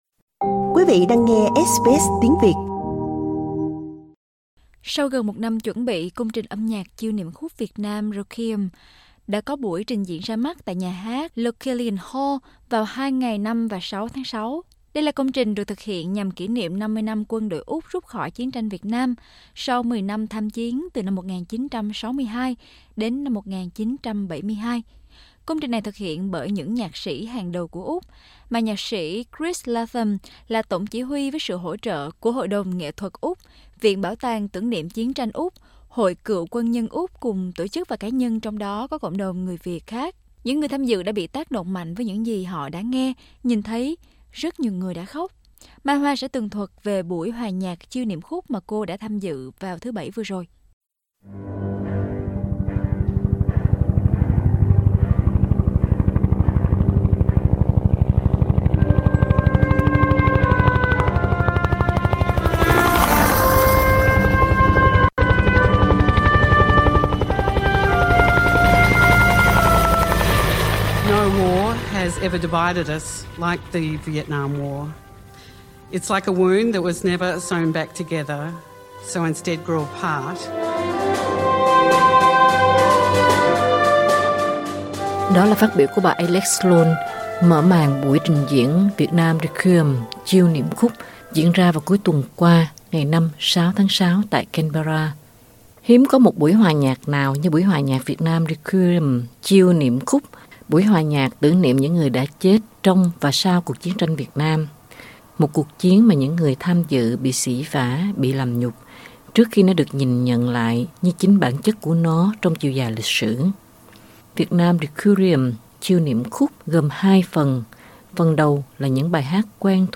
tường thuật về buổi hòa nhạc Chiêu Niệm Khúc mà cô tham dự